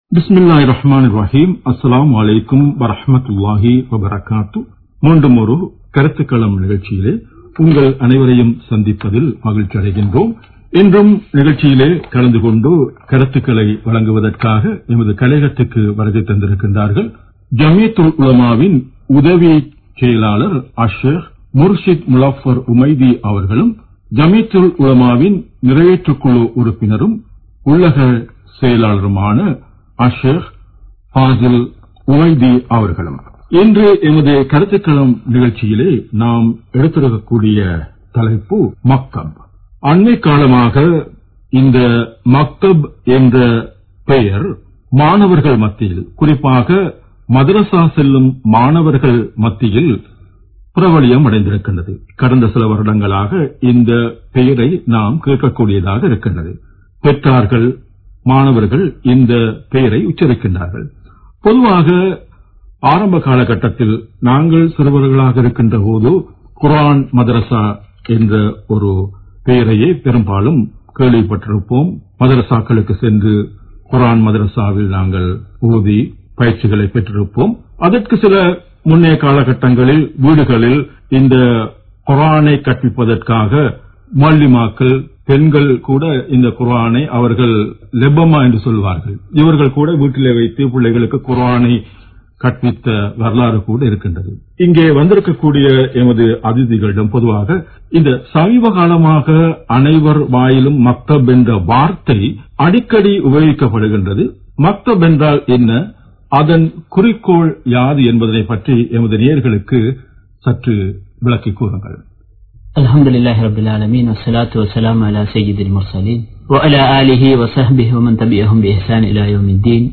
Makthab Thodarfana Kruththukkalam (மக்தப் தொடர்பான கருத்துக்களம்) | Audio Bayans | All Ceylon Muslim Youth Community | Addalaichenai